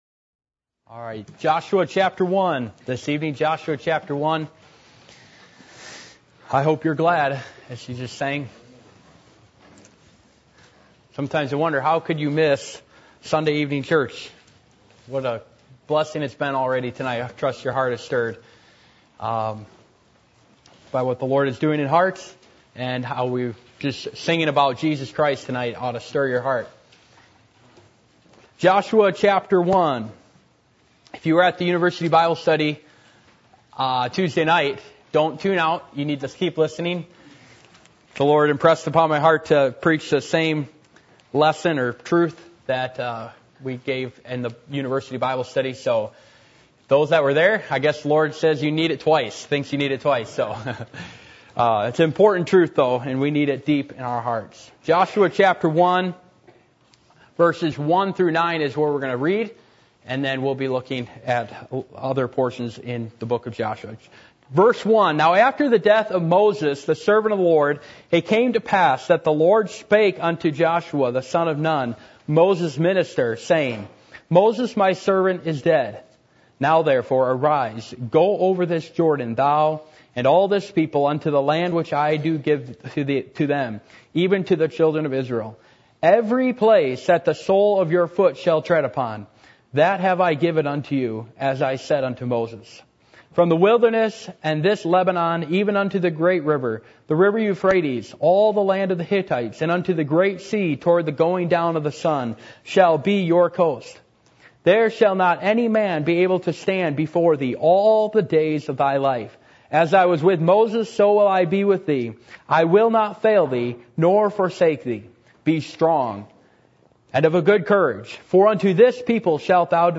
Passage: Joshua 1:1-9 Service Type: Sunday Evening %todo_render% « Are You Willing To Rise Up And Make A Difference?